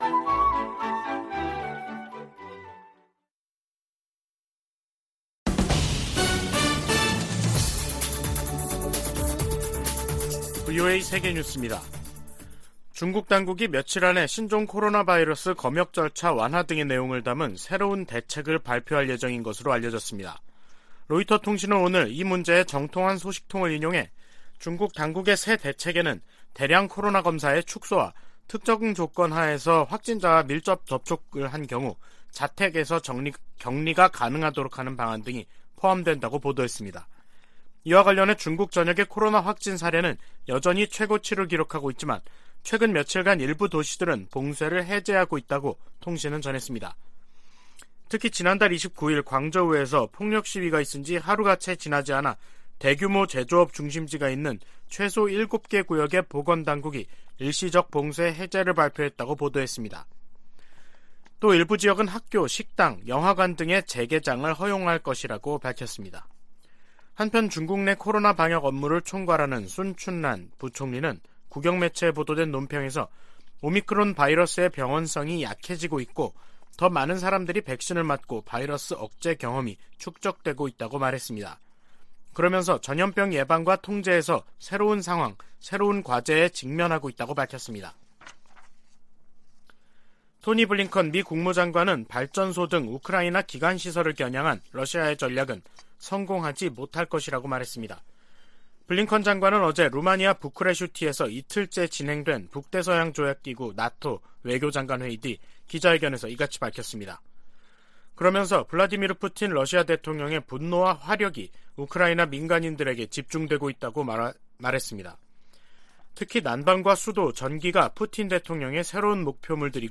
VOA 한국어 간판 뉴스 프로그램 '뉴스 투데이', 2022년 12월 1일 3부 방송입니다. 바이든 행정부는 한일 동맹과의 긴밀한 공조 속에 한반도의 완전한 비핵화를 계속 추진할 것이라고 제이크 설리번 백악관 국가안보보좌관이 밝혔습니다. 북한과 중국, 러시아, 이란, 시리아 지도자들은 국내 산적한 문제에도 불구하고 국제사회에 위협을 가하는 ‘불량배와 독재자’라고 미치 맥코넬 상원 공화당 원내대표가 비난했습니다.